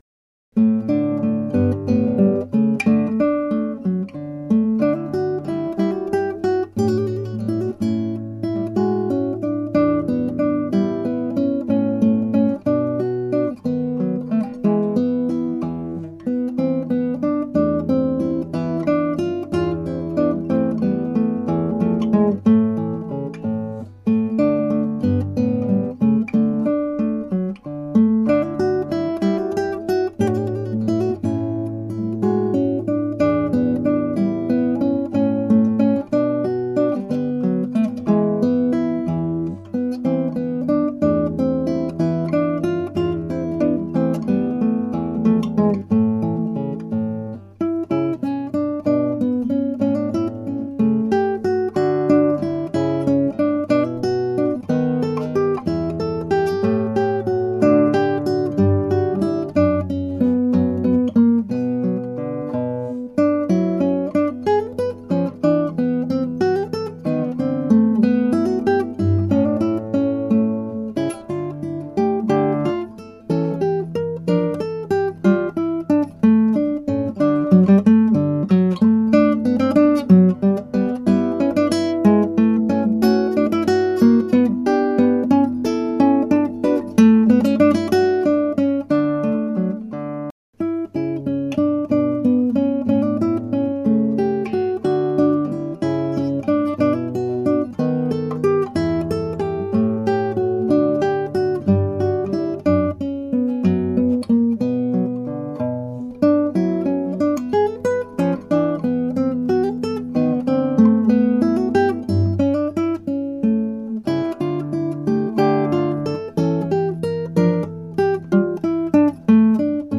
ギター演奏ライブラリ
(アマチュアのクラシックギター演奏です [Guitar amatuer play] )
原調はト長調ですがギター版はニ長調です。
16分音符でもたついたりテンポの揺らぎがあったりしています。
bach_bwv1007_gigue1.mp3